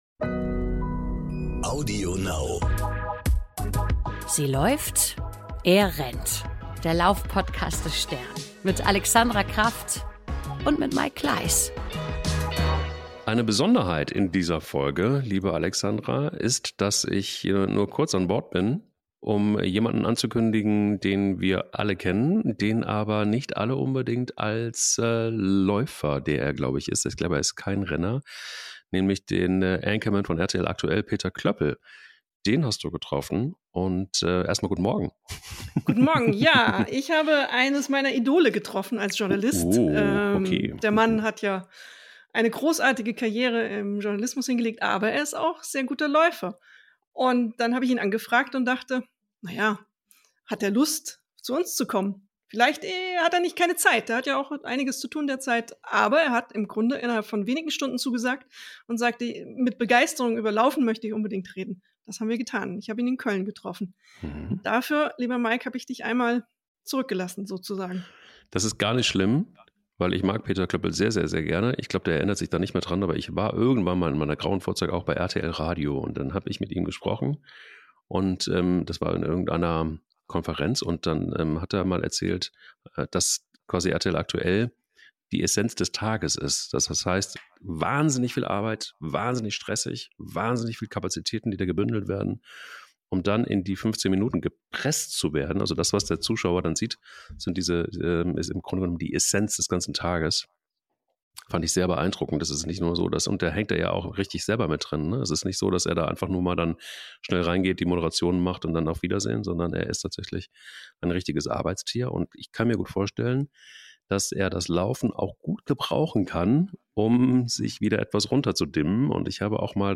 Wir haben ihn zum Gespräch getroffen. Und reden mit ihm über seine Marathon-Erfahrung, worüber er beim Training nachdenkt, wie er sein Gewicht hält und noch vieles mehr.